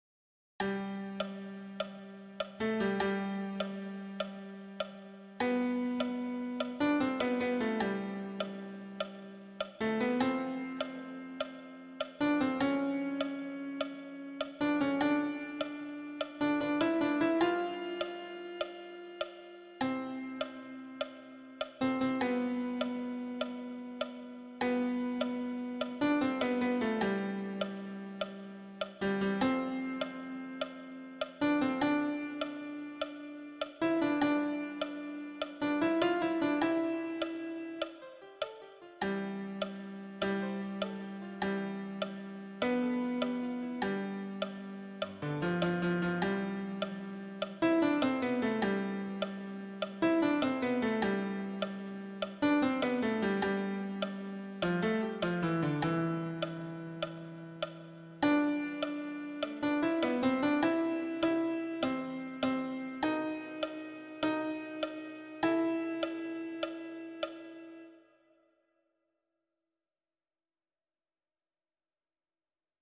Répétition de la pièce musicale
Répétition SATB par voix
Tenor
Non, je ne regrette rien_tenor-mix.mp3